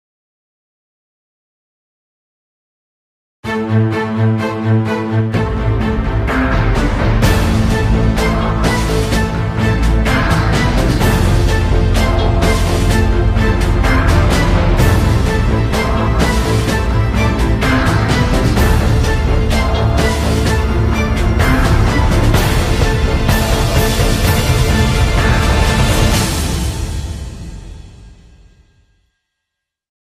Both classical and stylish music and sound effects…